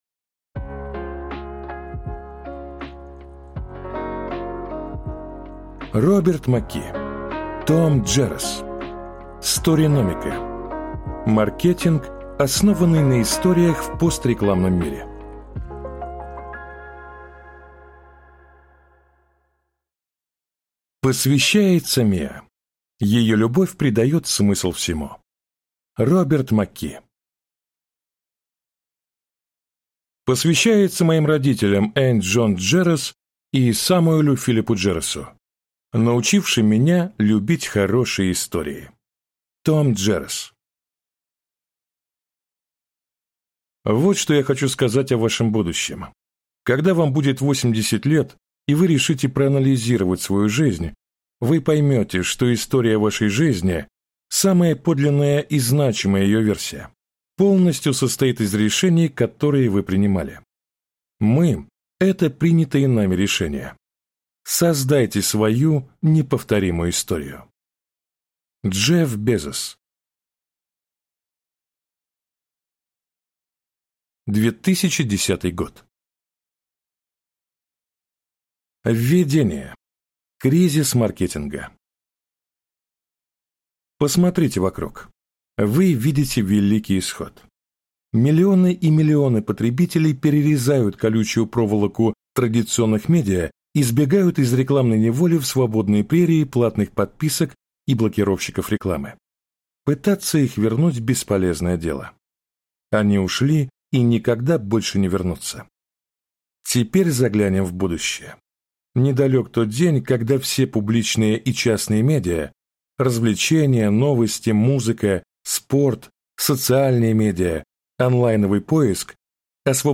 Аудиокнига Сториномика. Маркетинг, основанный на историях, в пострекламном мире | Библиотека аудиокниг